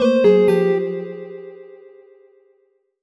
jingle_chime_21_negative.wav